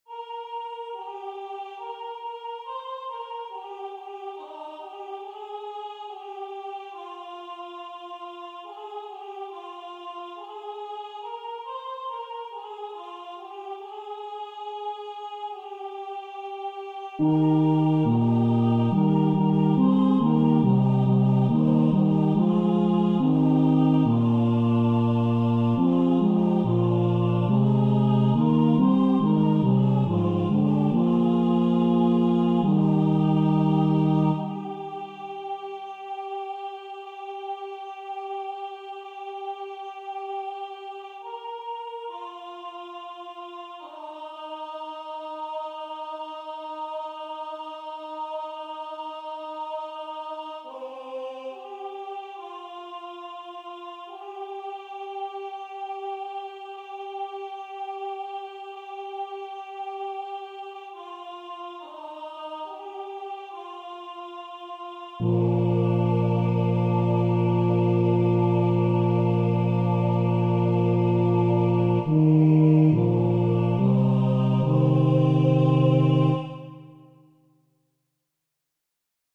Catégorie : Les Psaumes.